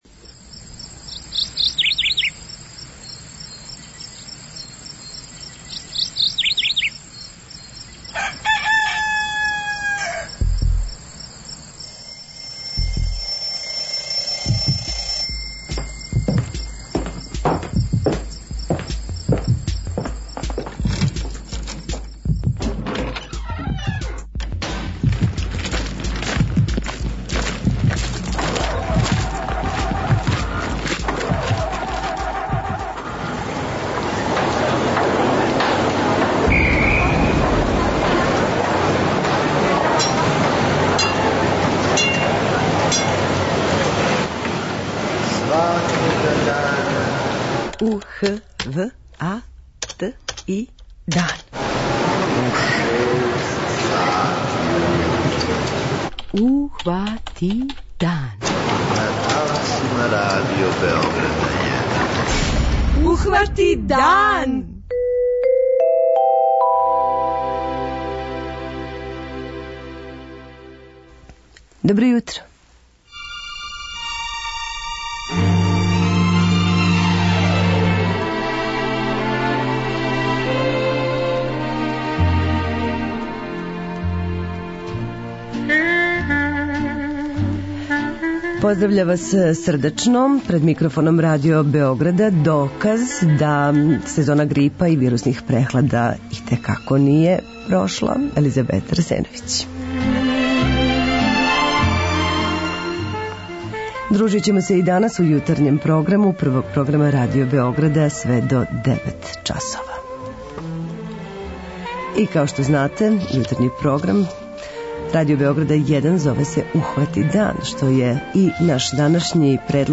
преузми : 43.16 MB Ухвати дан Autor: Група аутора Јутарњи програм Радио Београда 1!